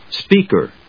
音節speak・er 発音記号・読み方
/spíːkɚ(米国英語), spíːkə(英国英語)/